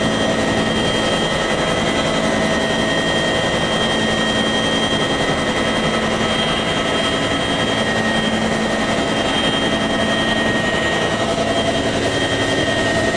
inside_noflap.wav